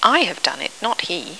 In inglese vengono scritte in corsivo quelle parole sulle quali, nel discorso, viene posto un accento enfatico (si tratta spesso di parole solitamente non accentate: verbi ausiliari, pronomi, ecc.):